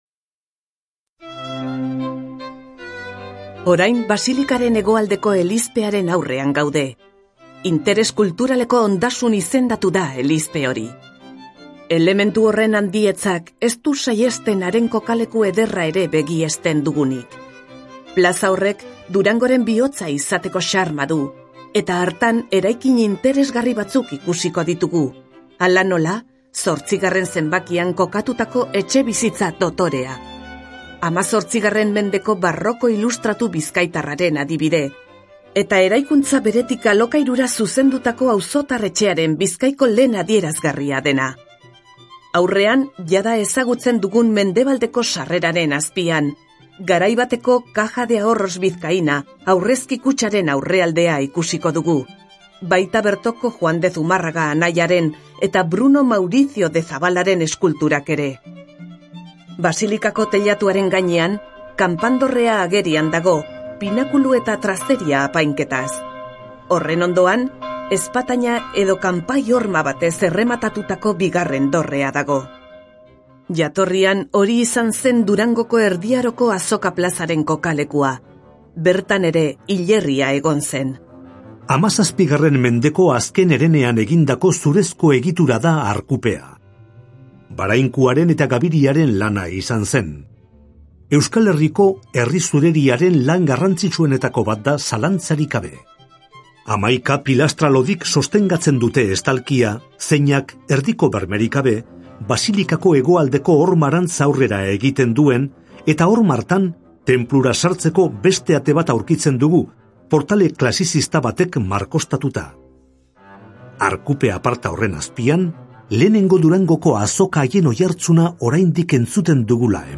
BISITA AUDIOGIDATUAK DURANGON - VISITAS AUDIOGUIADAS EN DURANGO